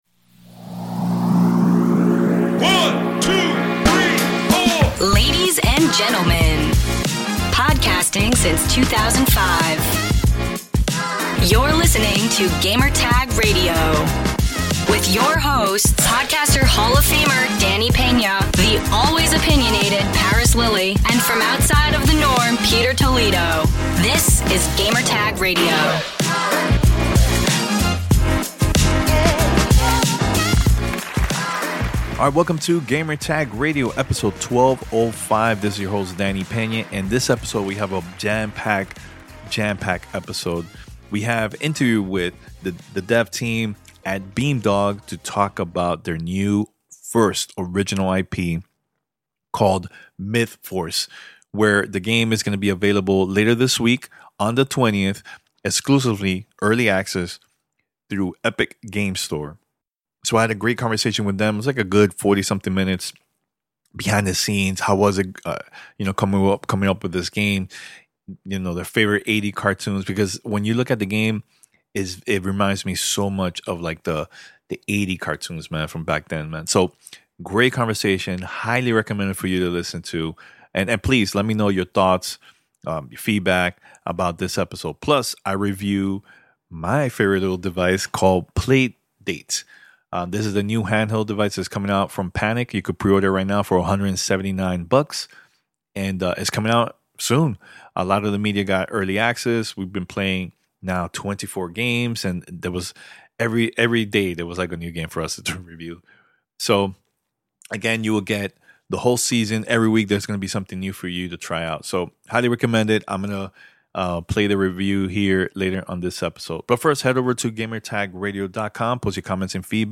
MythForce Interview and PlayDate Review